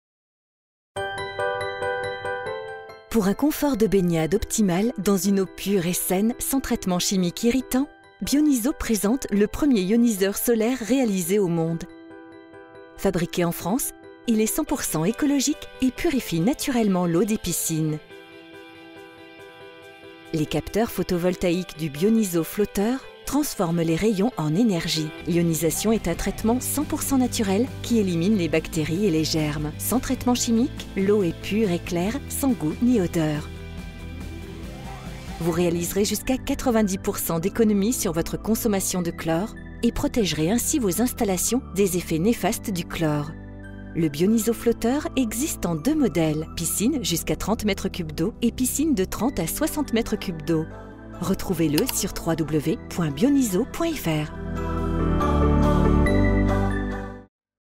Comédienne voix off féminine - adolescente, jeune, âge moyen, mûre...
Sprechprobe: Industrie (Muttersprache):